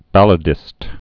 (bălə-dĭst)